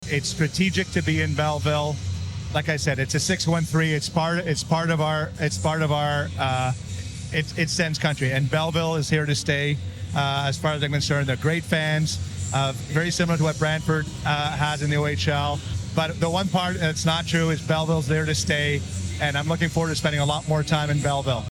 During a live intermission interview during Thursday night’s Ottawa Senators telecast on TSN,  Andlauer, wearing a  red and white ball cap with “613” emblazoned across the front (area code for Ottawa and Belleville) put rumours regarding the relocation of the B-Sens to bed.